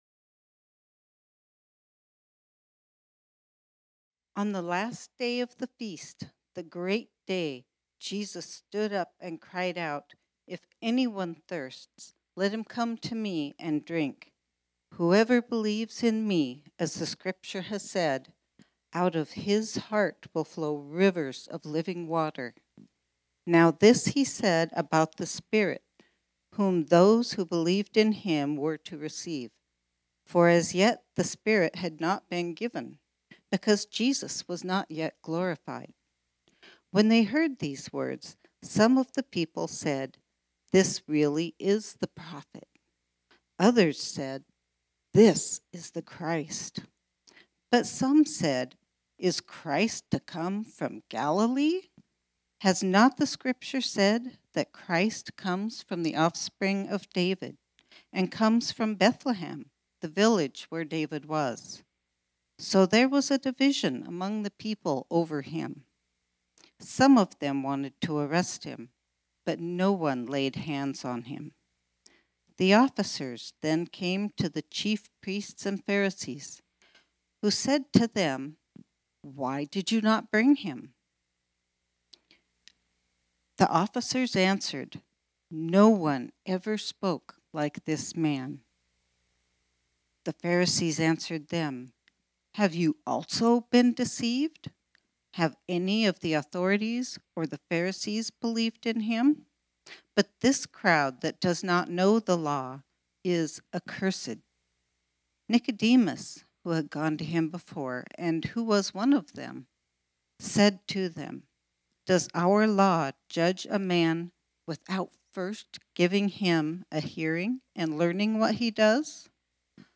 This sermon was originally preached on Sunday, December 1, 2019.